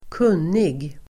Uttal: [²k'un:ig]